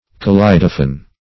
kaleidophon - definition of kaleidophon - synonyms, pronunciation, spelling from Free Dictionary
Kaleidophon \Ka*lei"do*phon\, Kaleidophone \Ka*lei"do*phone\,